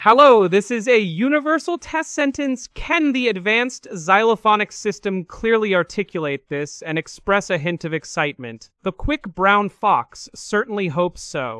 Open-Source-TTS-Gallary
Open-Source-TTS-Gallary / samples /sesame_csm-1b /generated-audio.wav